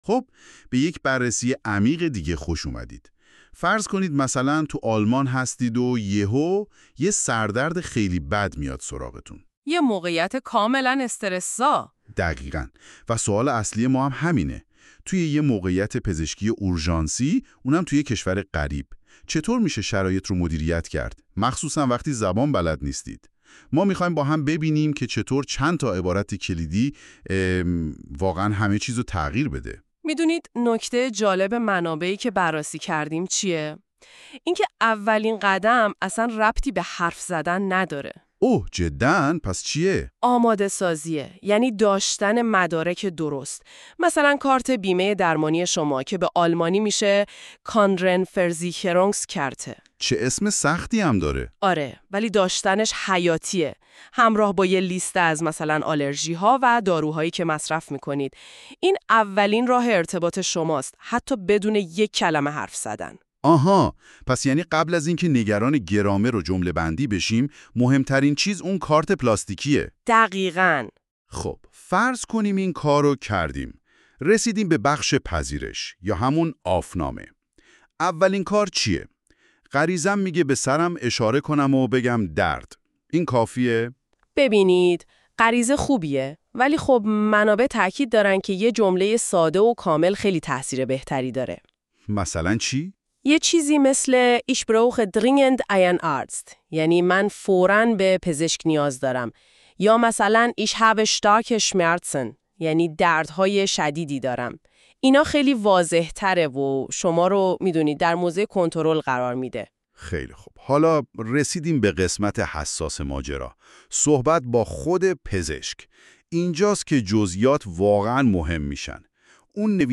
german-hospital-conversation.mp3